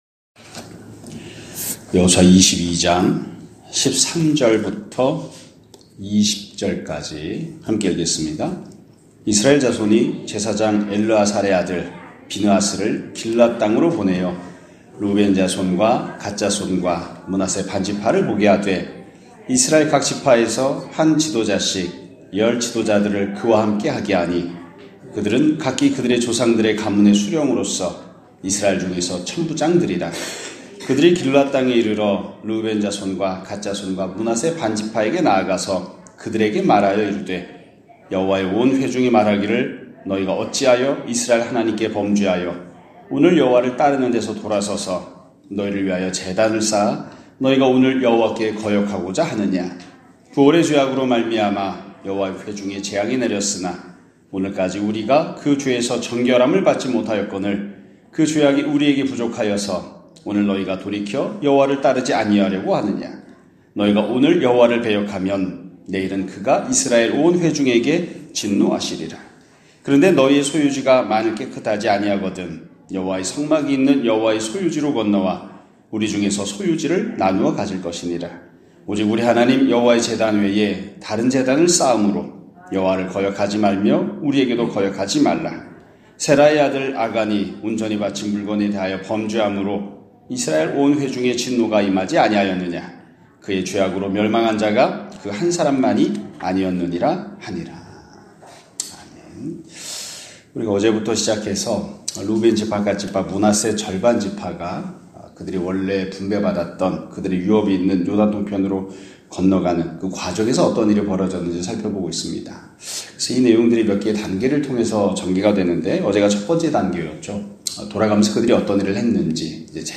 2025년 2월 11일(화요일) <아침예배> 설교입니다.